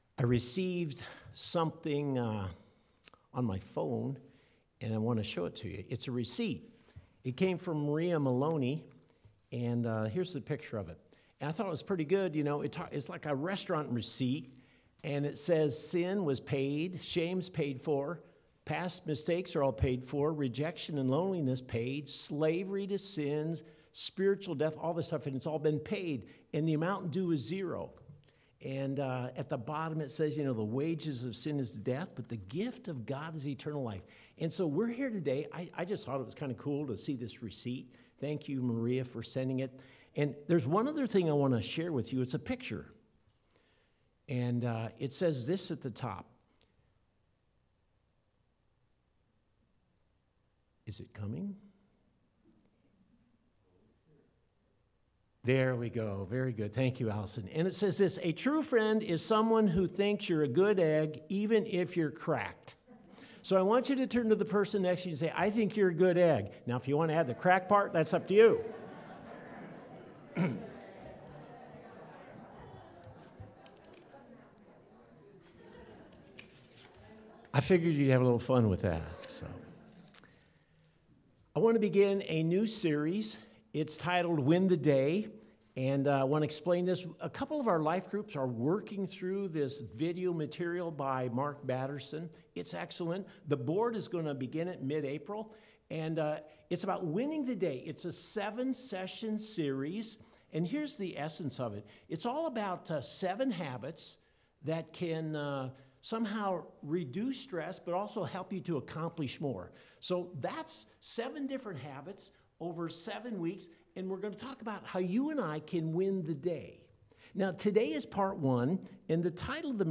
4-4-21-Flip-the-Script-Early-Easter-Service.mp3